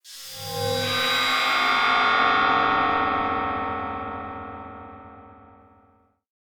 • Качество: 321, Stereo
без слов
пугающие
страшные